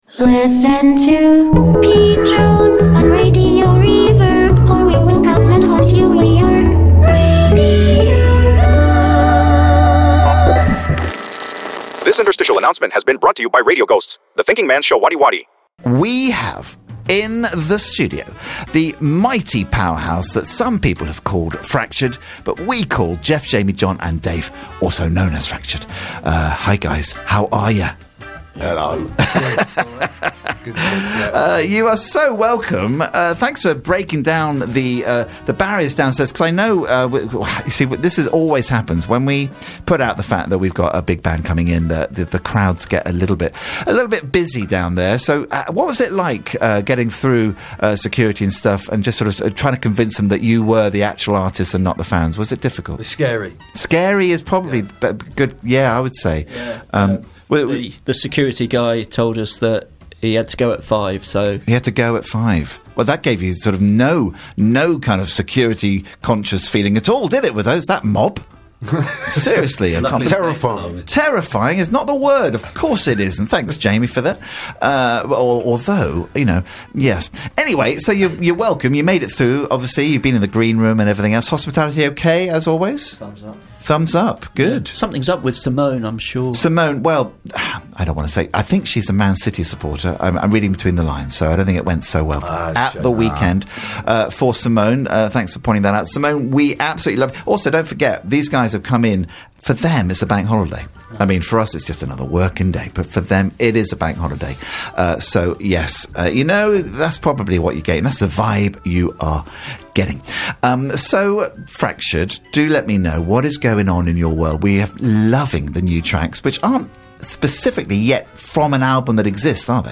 Live chat with Fractured 27th May 2024